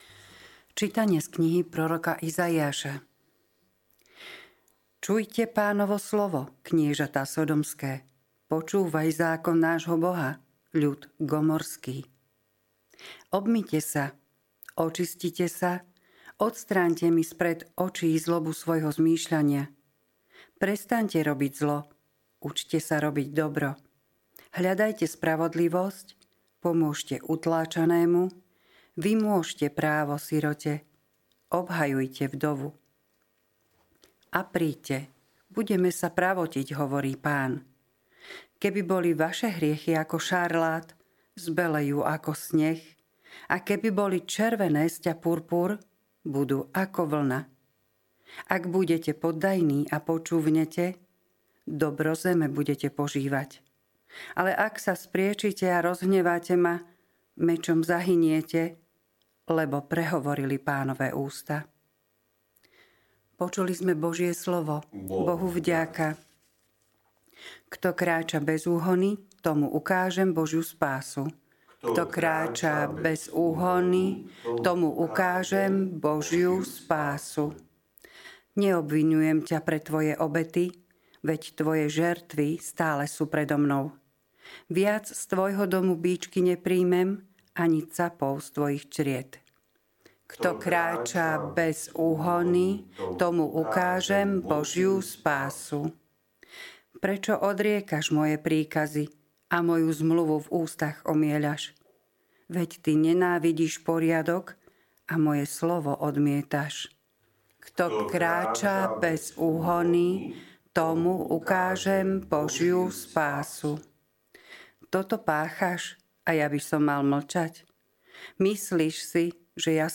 LITURGICKÉ ČÍTANIA | 18. marca 2025